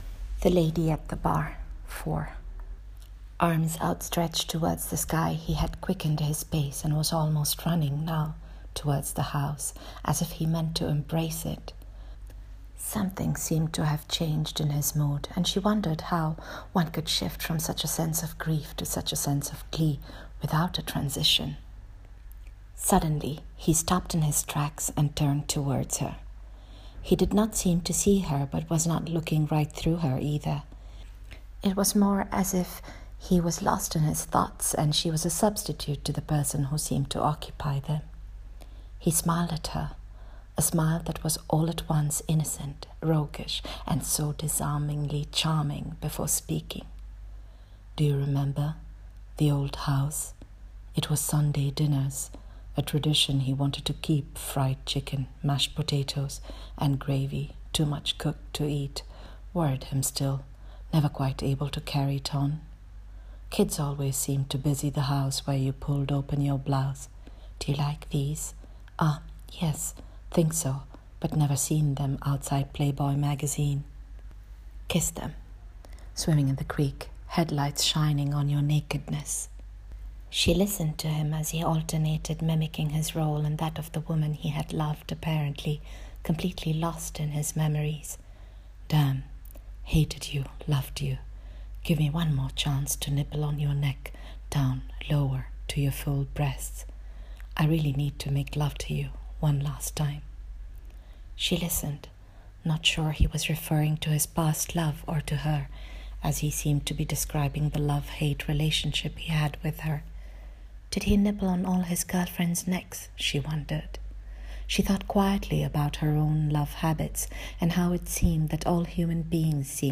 Reading of this episode of the story: